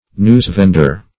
News-vender \News"-vend`er\, n. A seller of newspapers.